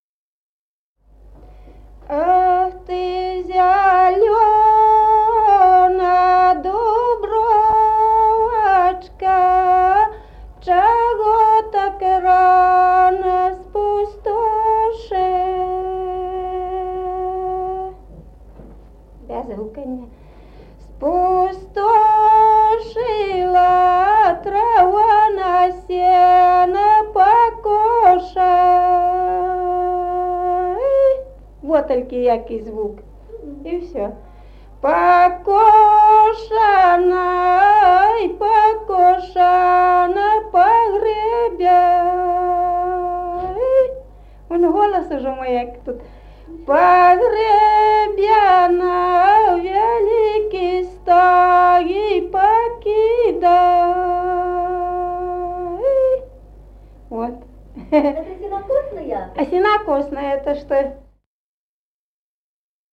Народные песни Стародубского района «Ох, ты зелёна дубровочка», покосная.
с. Мохоновка.